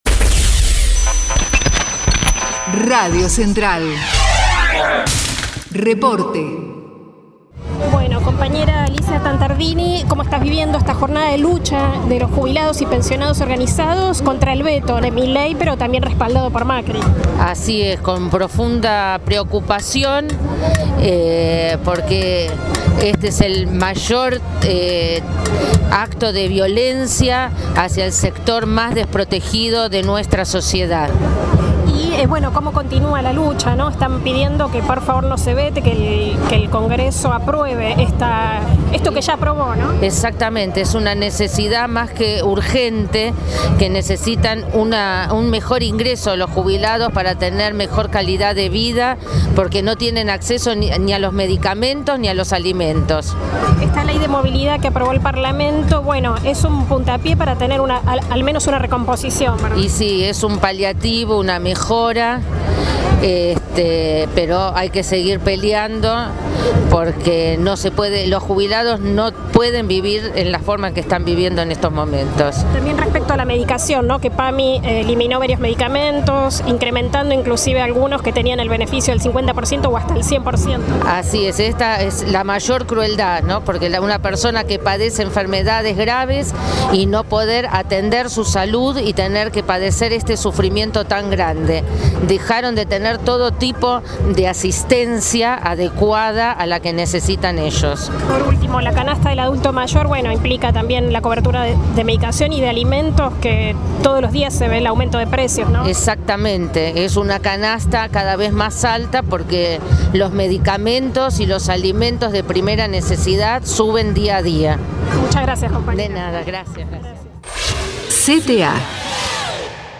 Jubilados y Pensionados: Marcha a Plaza de Mayo - Testimonios CTA